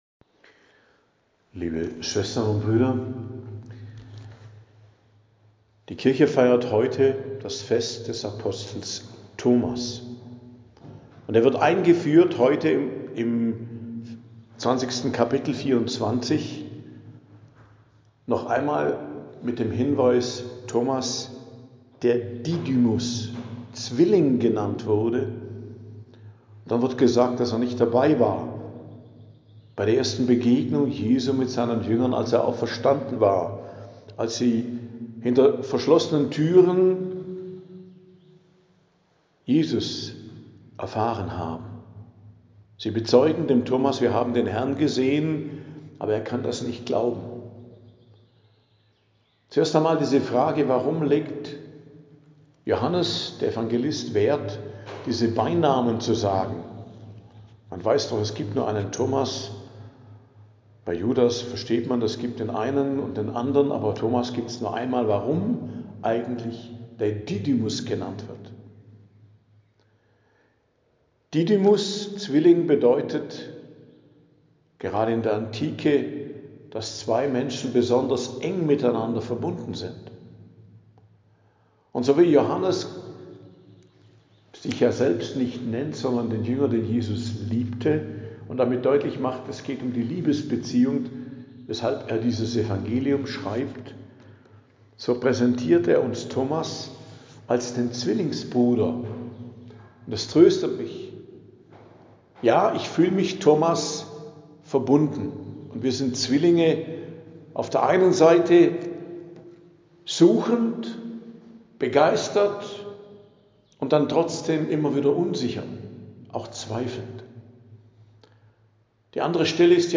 Predigt am Fest des Hl. Thomas, Apostel, 3.07.2025